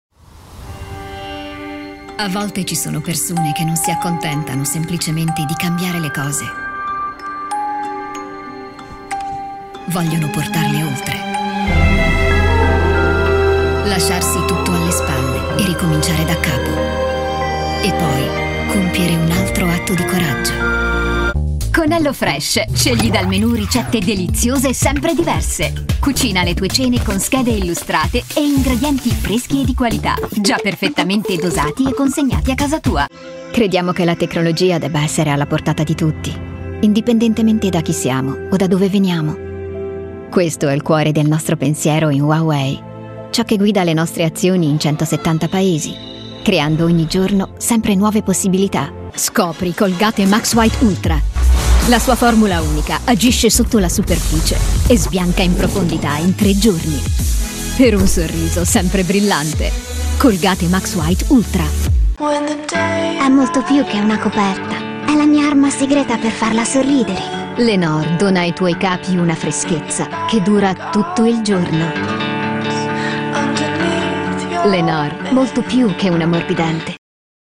European, Italian, Female, Home Studio, Teens-30s
Home Studio Read